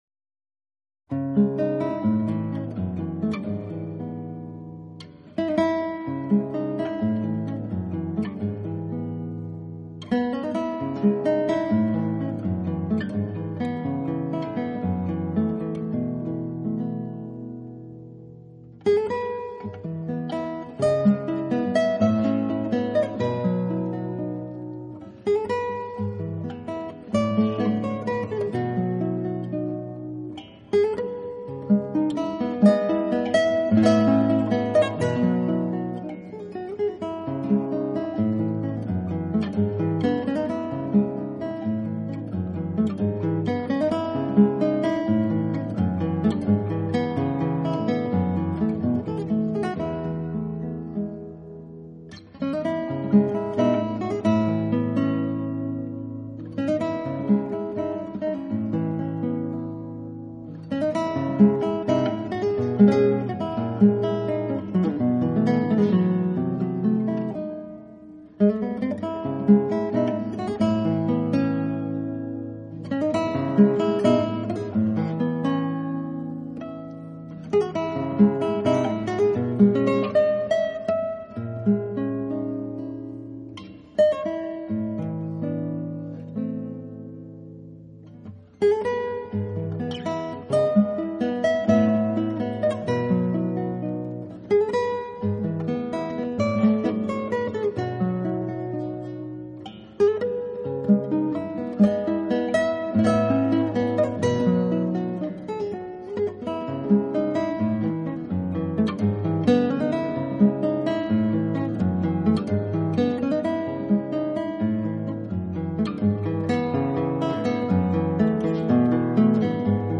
他的吉他是纯绿的林野，你几乎可以闻到芬多精的召唤，即使你正在破旧的加油站。